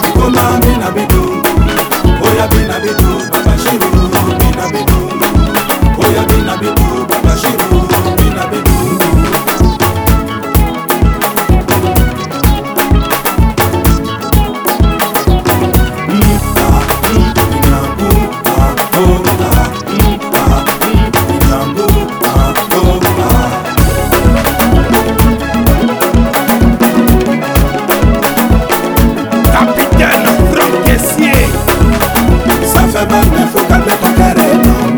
Afro-Beat, African